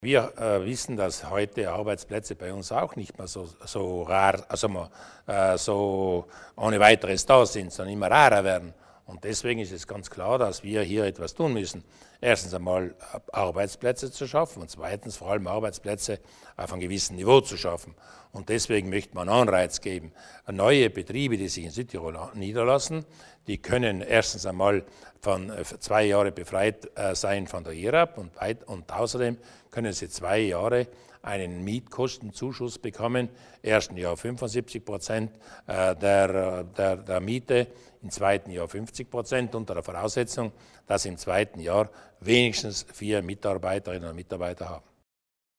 Landeshauptmann Durnwalder zu den Maßnahmen für die Wirtschaft